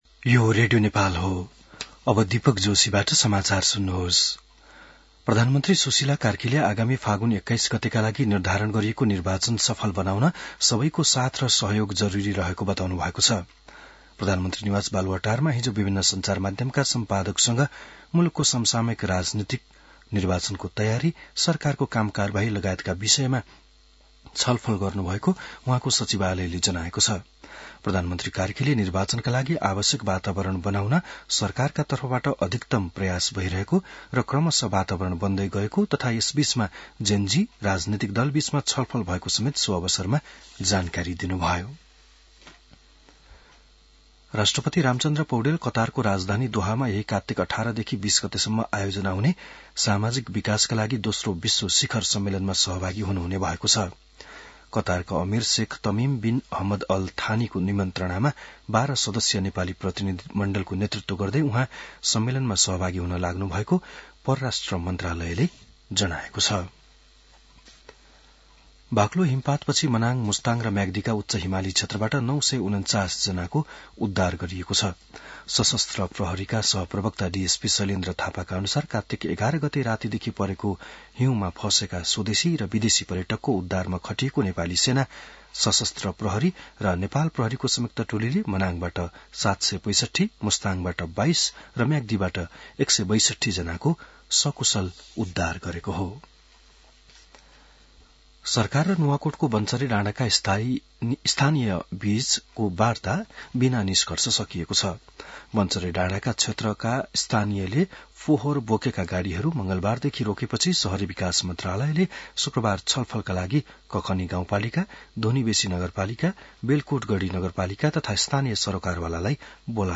An online outlet of Nepal's national radio broadcaster
बिहान १० बजेको नेपाली समाचार : १५ कार्तिक , २०८२